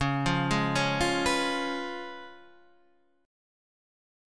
Db7 chord